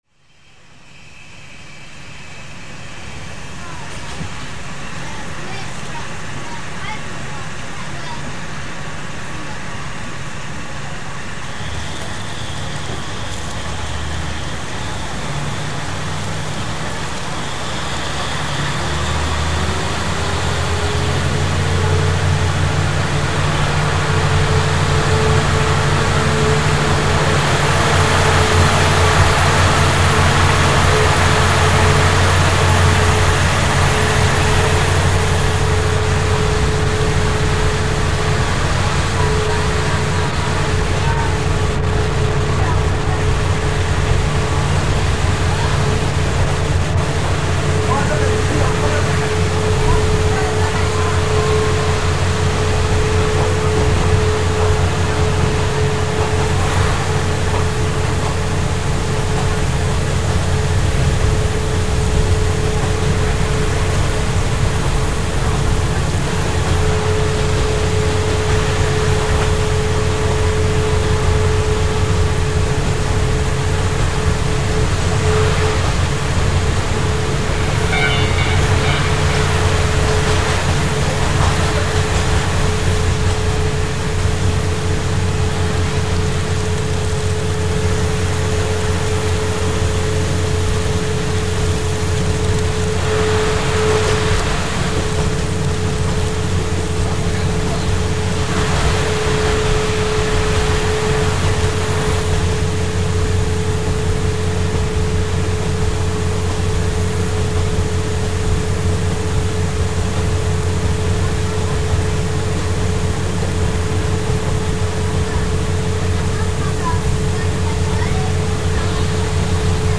（走行音）MP3　4400KB　6分25秒